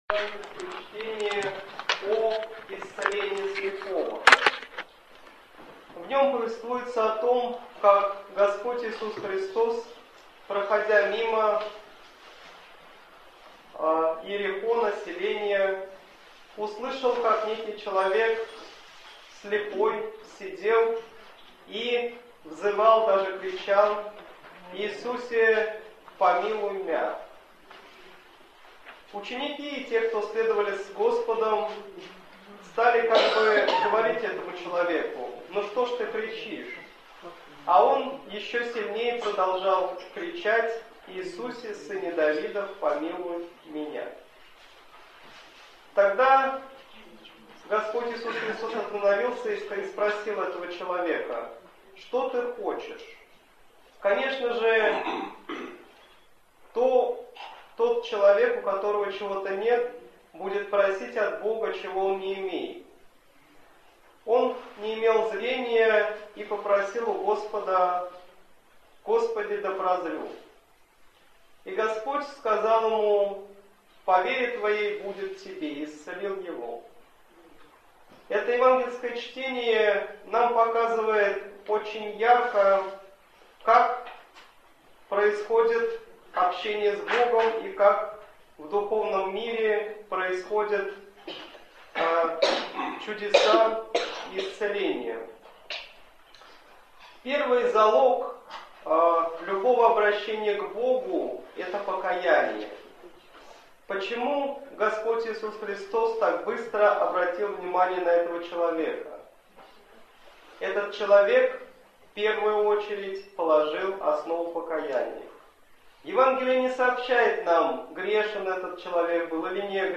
Слово в неделю 36-ю по Пятидесятнице